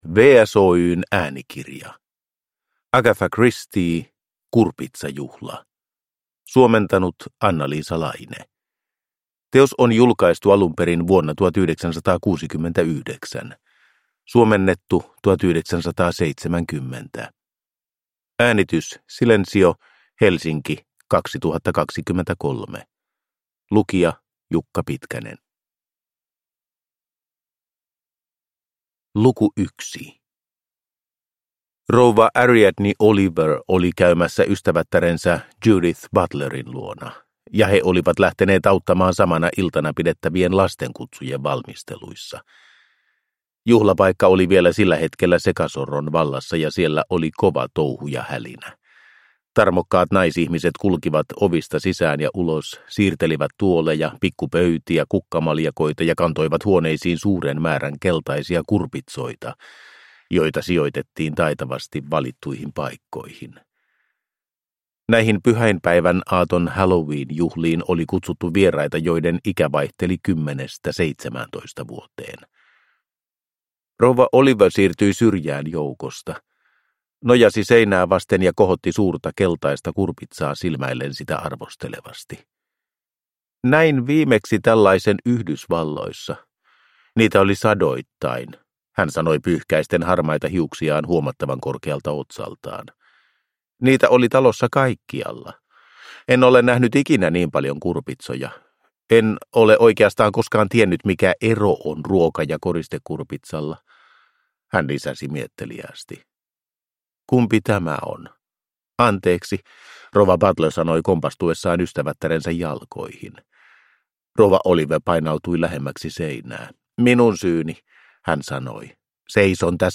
Kurpitsajuhla – Ljudbok – Laddas ner